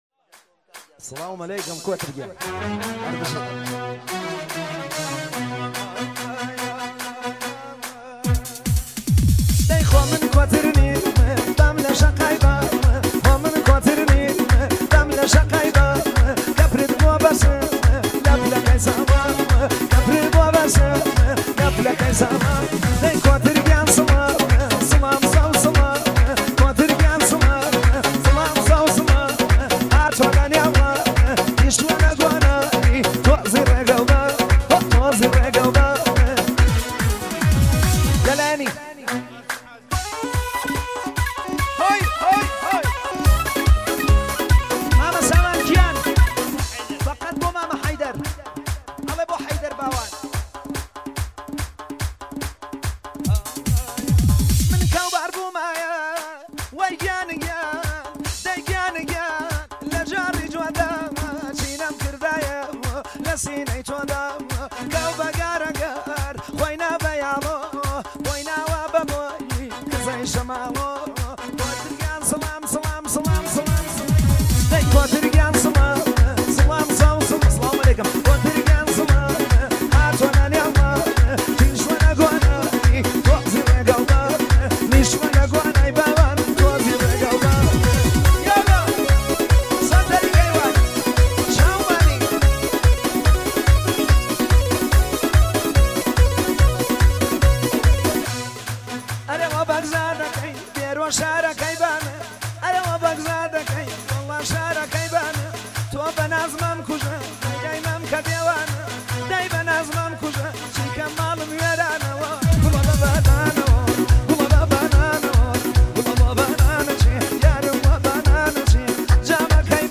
آهنگ کردی شاد